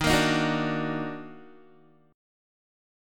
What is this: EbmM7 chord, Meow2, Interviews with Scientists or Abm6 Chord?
EbmM7 chord